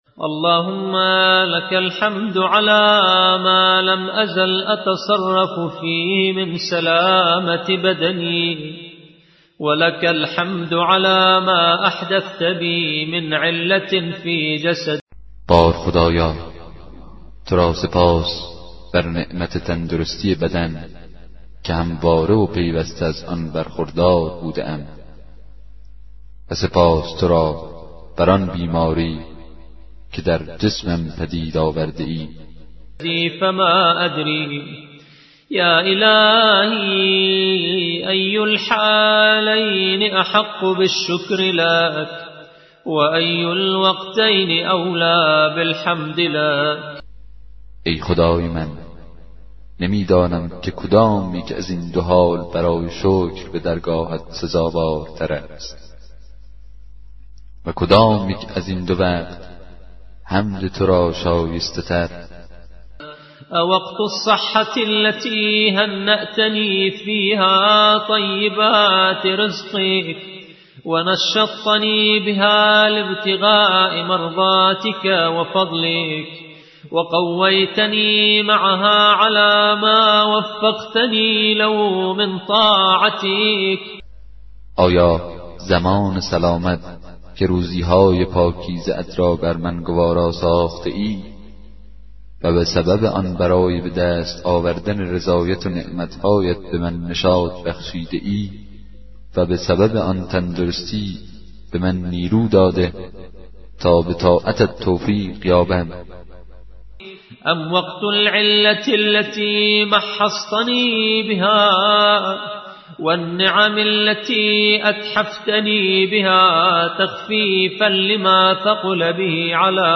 کتاب صوتی دعای 15 صحیفه سجادیه